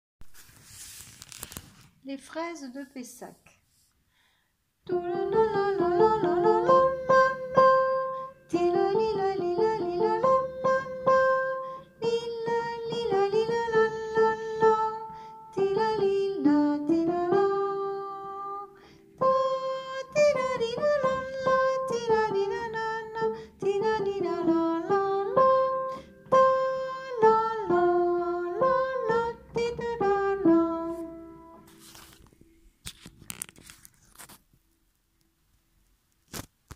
Un chant de célébration à la Fraise de Pessac, deviendra un emblême musical des festivités de la ville.